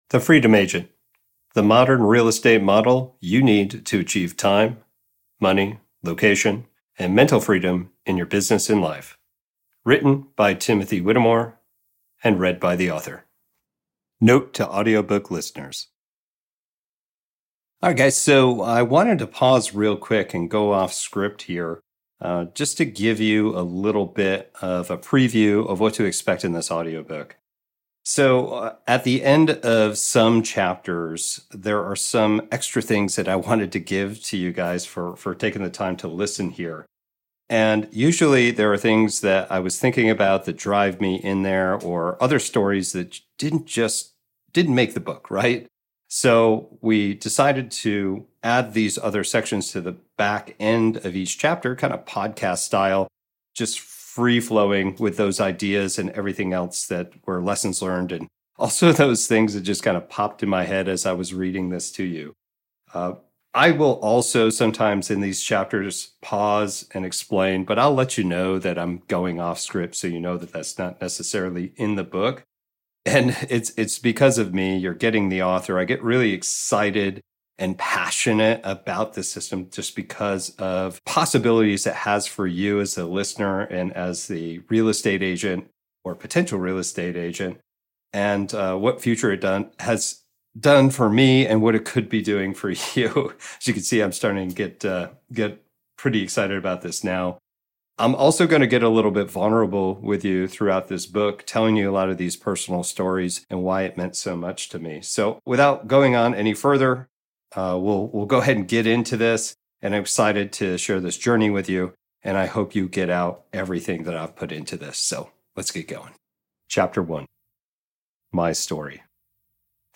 FREE AUDIOBOOK PREVIEW
the-freedom-agent_audiobook_preview_chapters1-4-ILPRAyu5JEKdEl7W.mp3